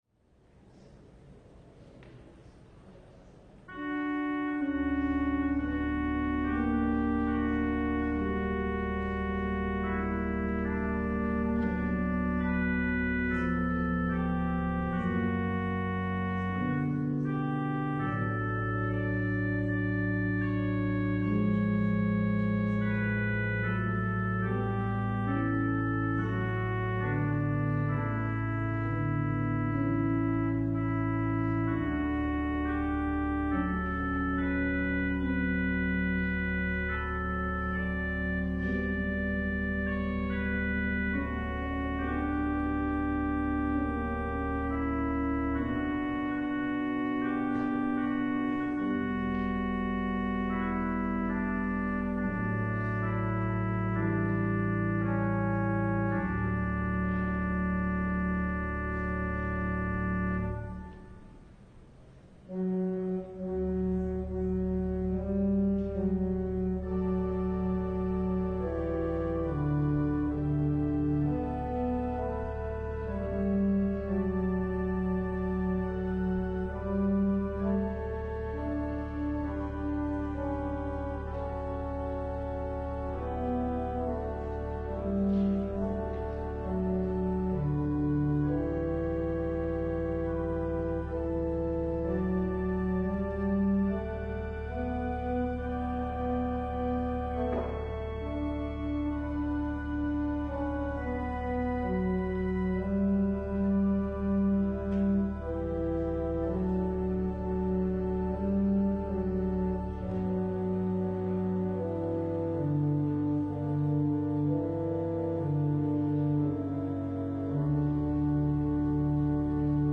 LIVE Evening Worship Service - Out of the Heart